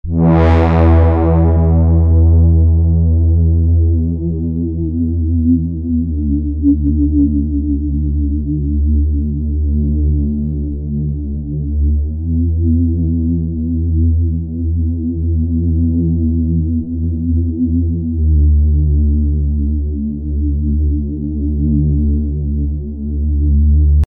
den GRM Doppler anstelle eines Chorus (hier animiere ich den Mixregler und die Stärke der Tonhöhenmodulation durch den Doppler),
und schließlich noch die LA2A-Emulation von Waves (Kompressor), um den Klang kompakter zu machen.